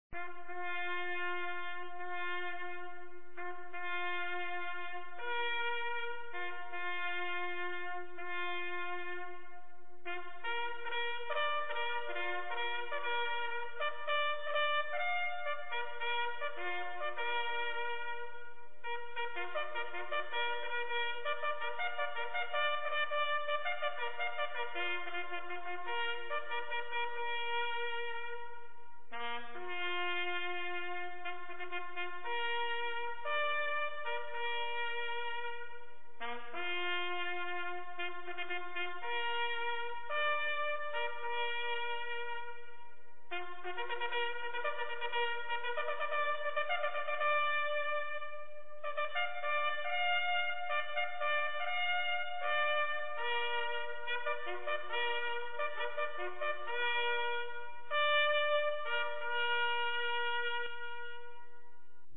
BUGLE CALLS
REVEILLE
reveille_ba712x.wav